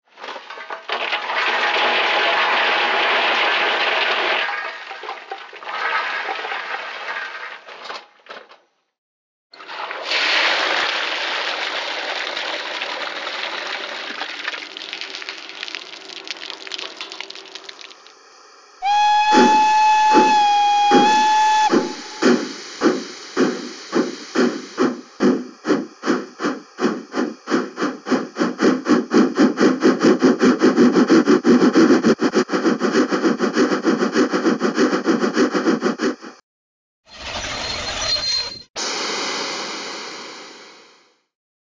Märklin 39490 Dampflok F 1200 der SJ, AC 3L, digital mfx+/Sound/Rauch - H0 (1:87)
Märklin 39490 Demo-Sound.mp3